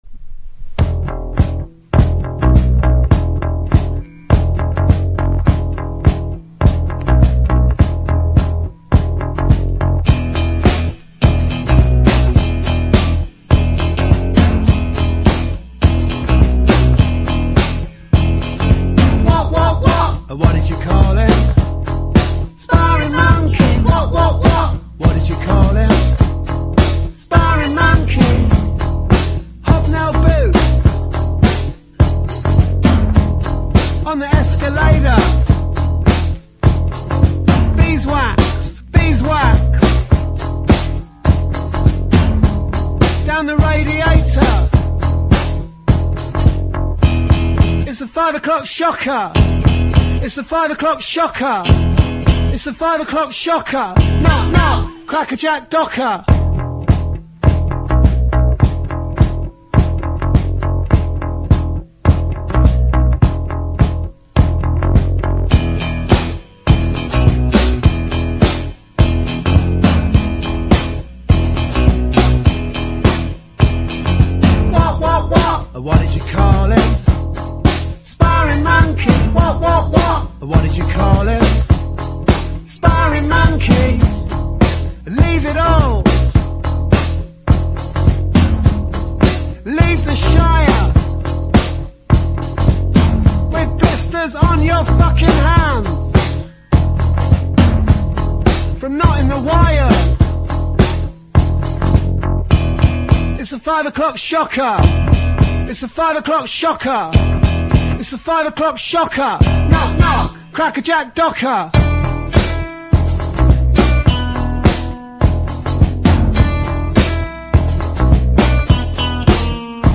# INDIE DANCE
太いベースにミッドテンポなドラムが響く極悪バンド